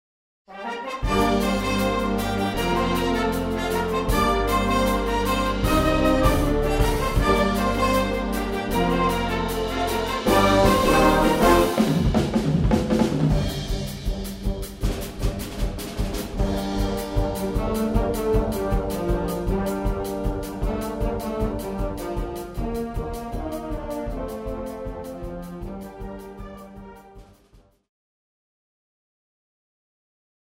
Wind Orchestra Grade 3-5